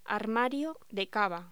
Locución: Armario de cava